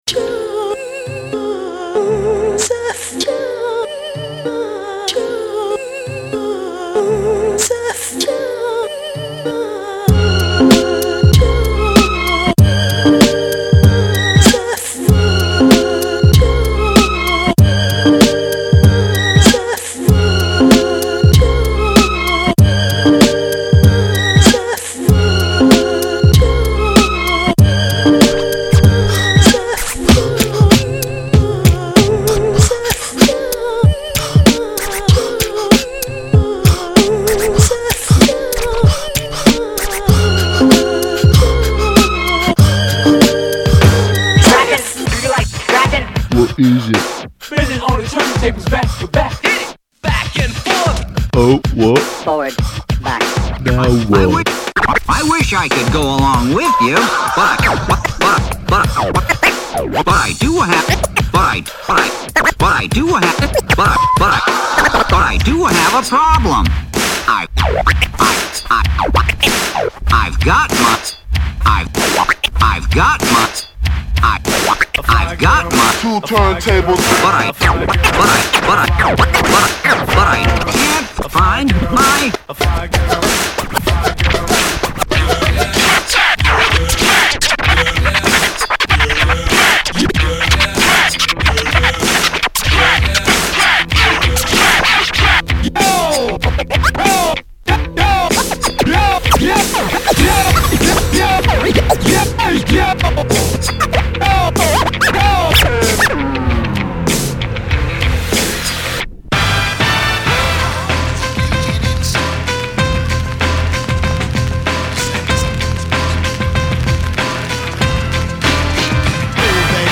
Styl: Hip-Hop
MP3 Stereo